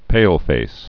(pālfās)